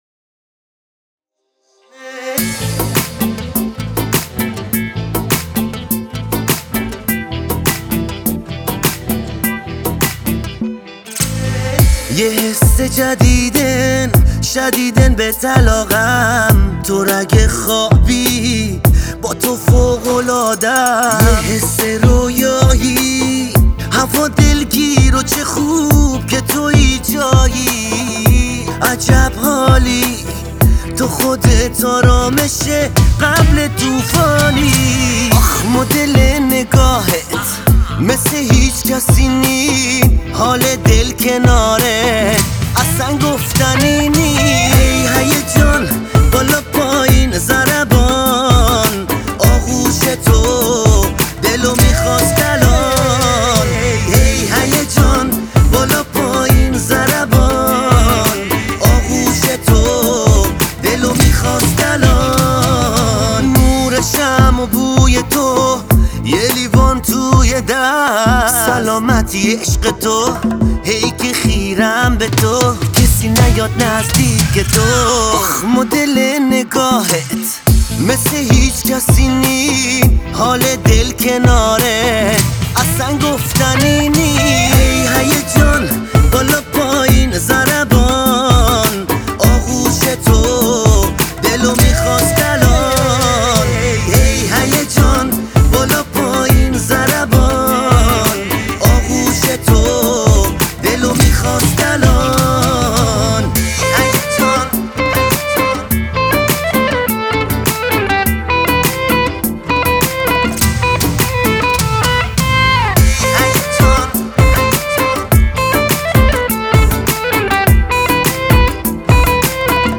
آهنگ بستکی